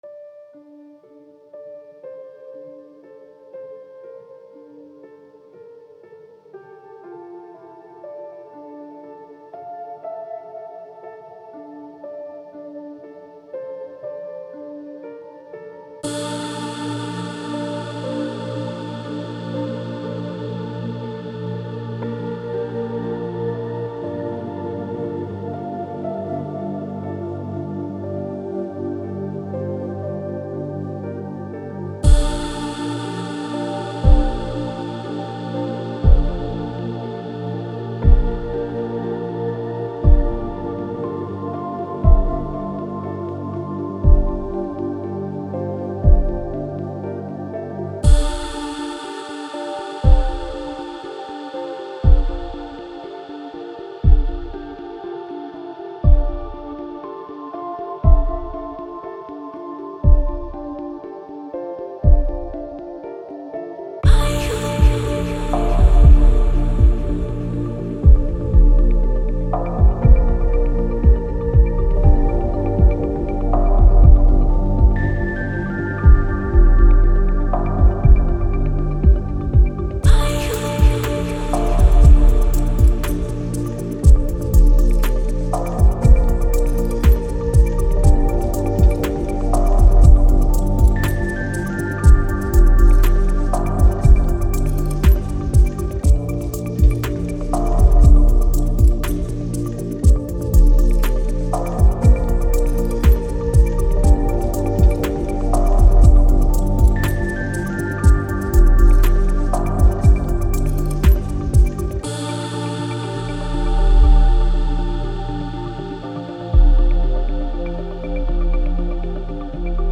Tags: Garage